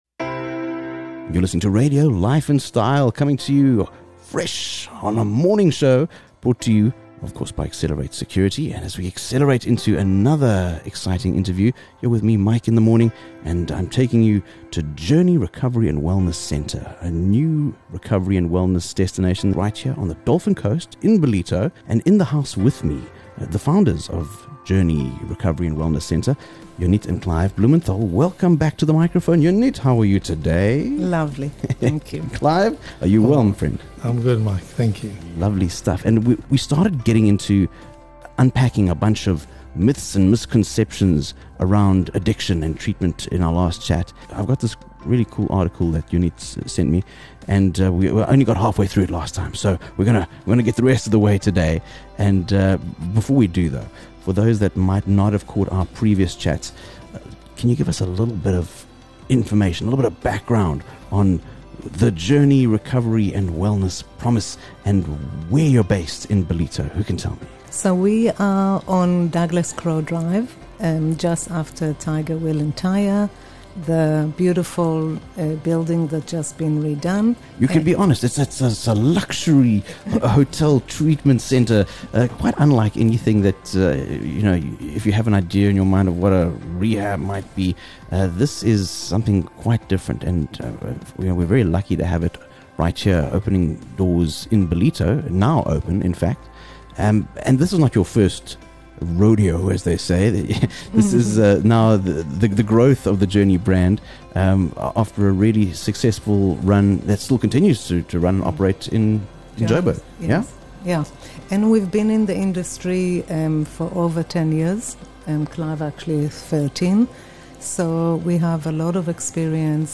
an illuminating 2-part discussion about the common myths & misconceptions that surround the topics of addiction and recovery.